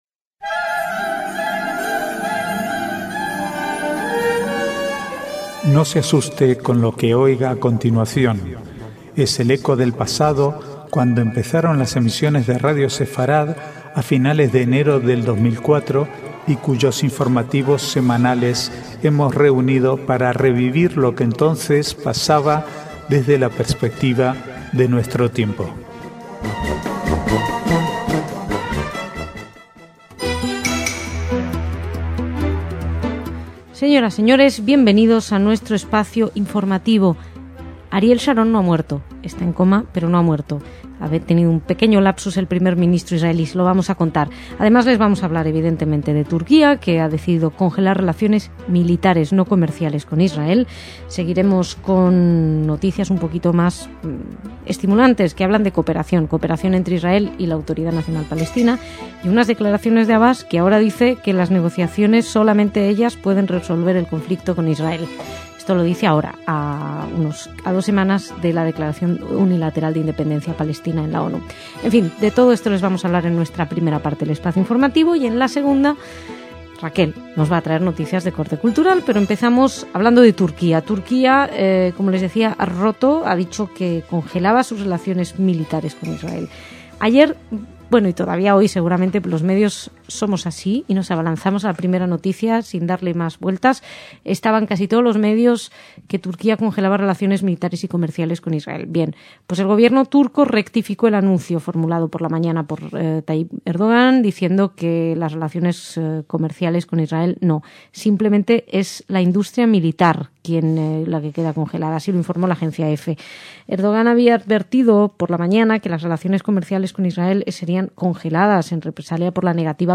Archivo de noticias del 7 al 13/9/2011